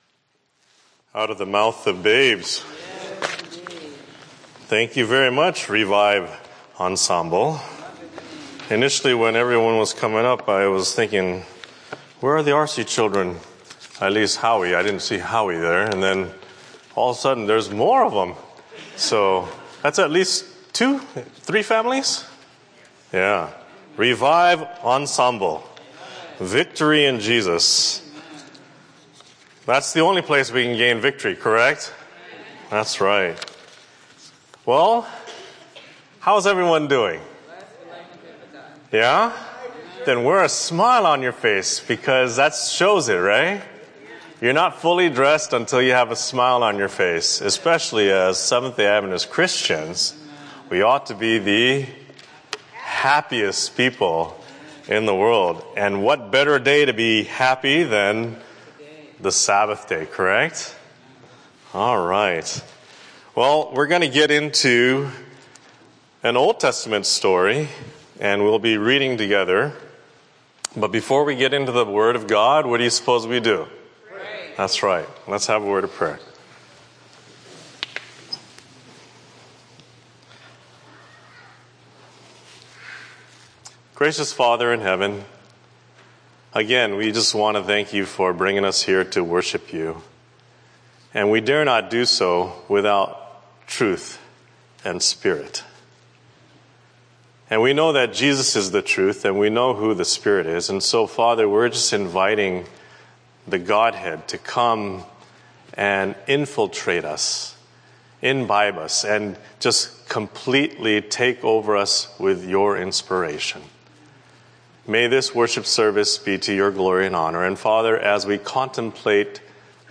——————————————————- {Alternately, the last part of this Sermon could be titled “From Formalism to Power,” starting at 50:42.} – Ed.’s note Romans 1:16 For I am not ashamed of the gospel of Christ: for it is the power of God unto salvation to every one that believeth; (…)